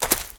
STEPS Leaves, Walk 15.wav